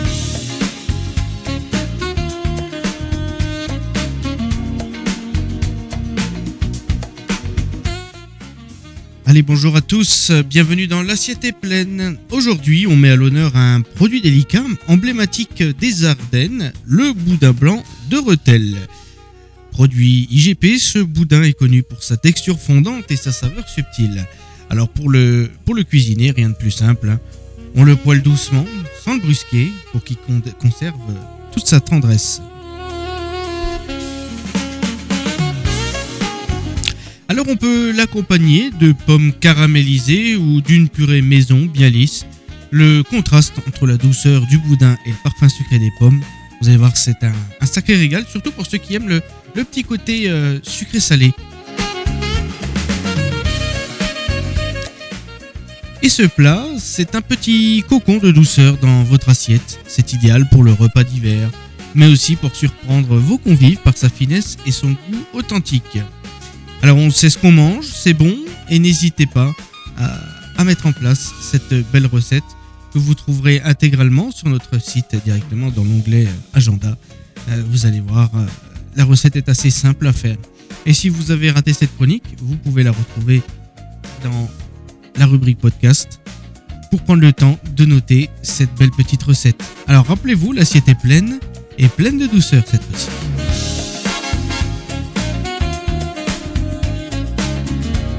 Chaque semaine sur Antenne 87, partez à la découverte d’un plat typique ou d’une recette oubliée des Plaines de Champagne, dans la chronique culinaire "L’Assiette est Plaines".